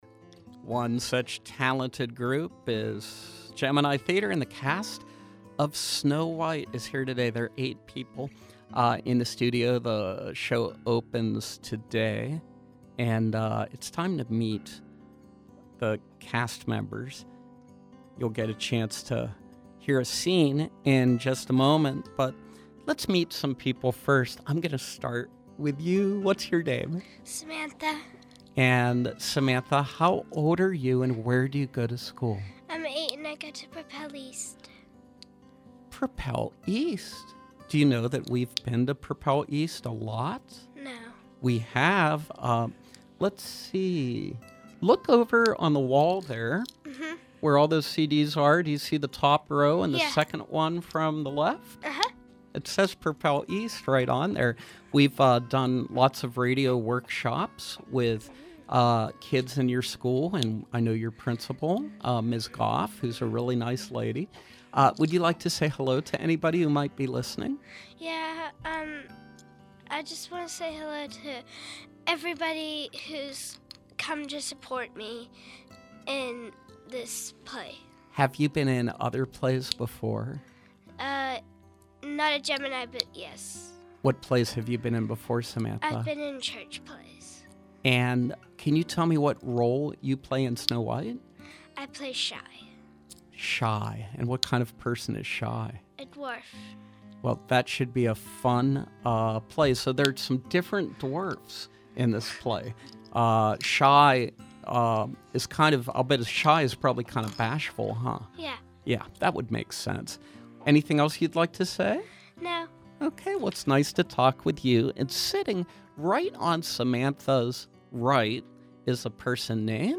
We welcome members of the cast of ‘Snow White’ as they preview their production of this classic story.